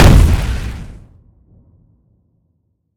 small-explosion-2.ogg